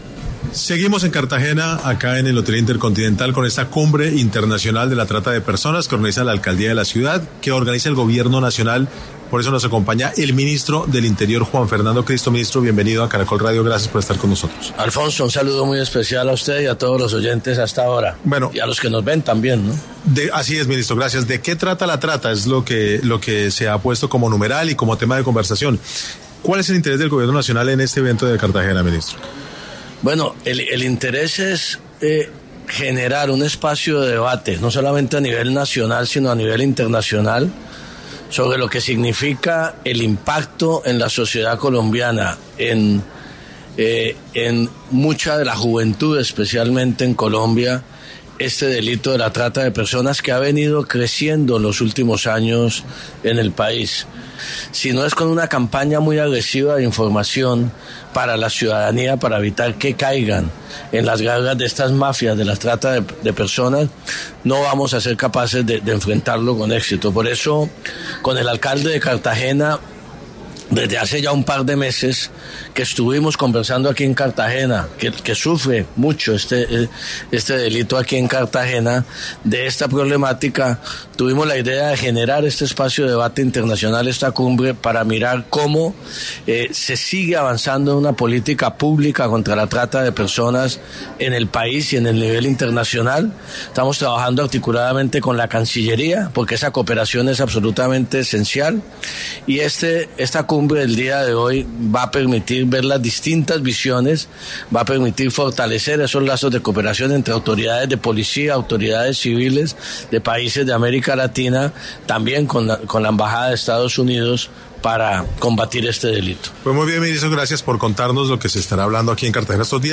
En 6AM de Caracol Radio estuvo el ministro del Interior, Juan Fernando Cristo, para hablar sobre la decisión del presidente Gustavo Petro de nombrar a Armando Benedetti como asesor político.